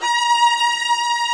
STR VIOLA09R.wav